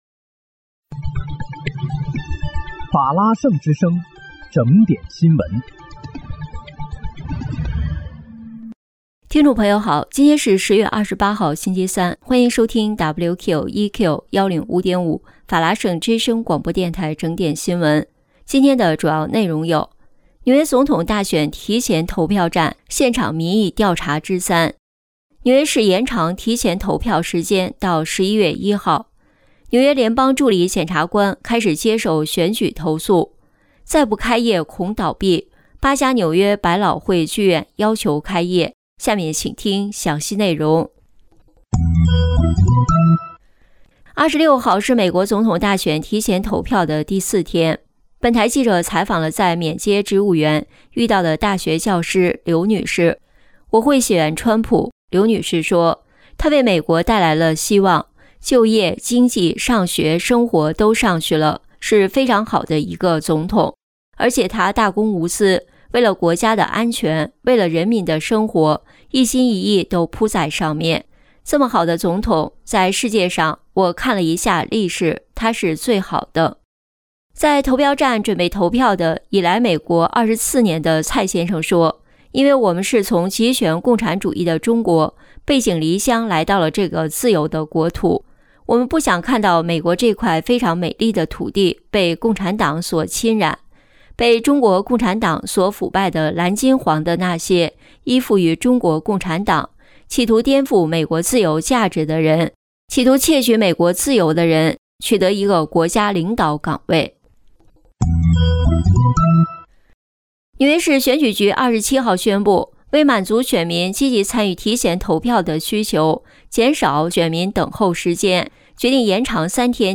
10月28日（星期三）纽约整点新闻
听众朋友您好！今天是10月28号，星期三